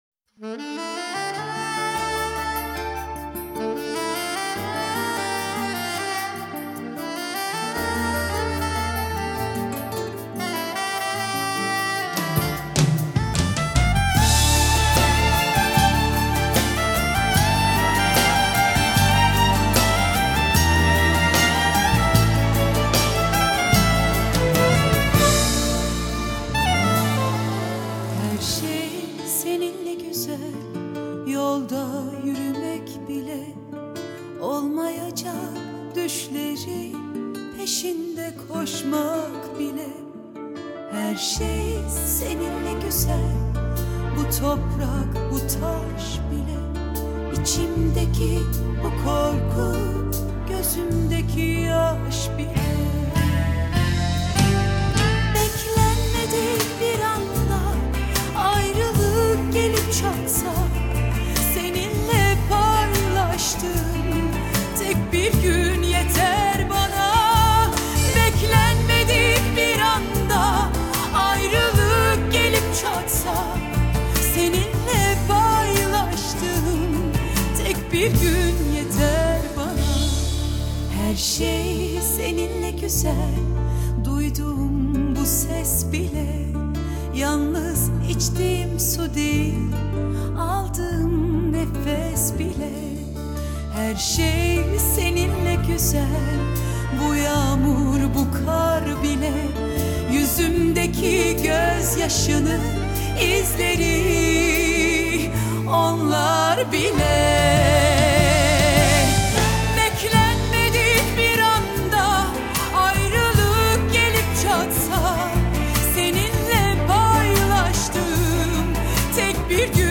اهنگ ترکی برای رقص تانگو با صدای زن